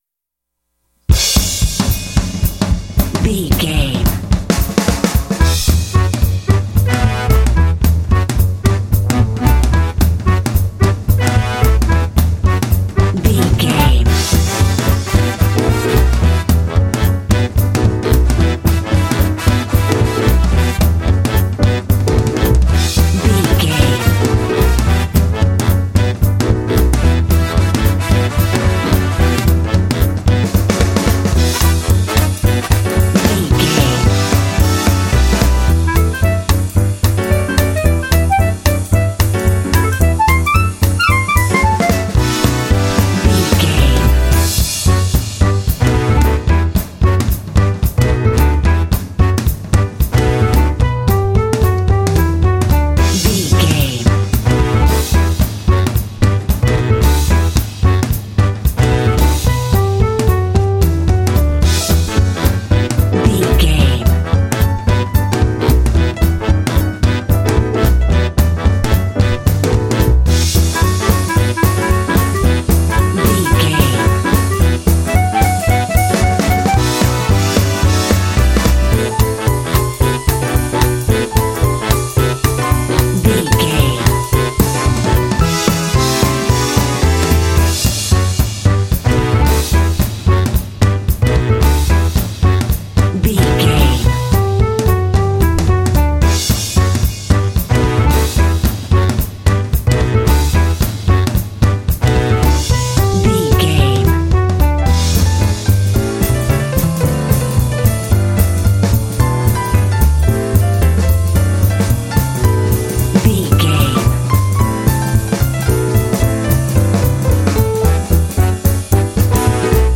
Uplifting
Aeolian/Minor
Fast
energetic
lively
cheerful/happy
drums
piano
brass
double bass
big band
jazz